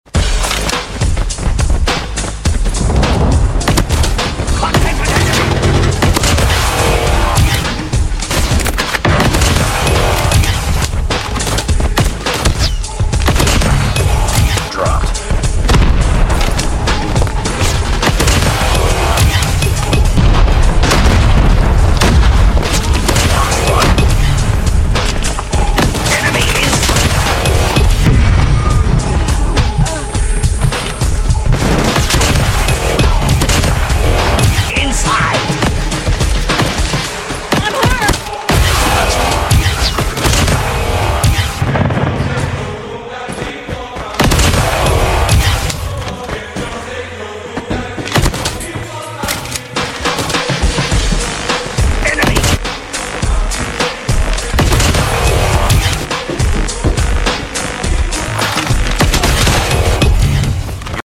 hmmm sound effects free download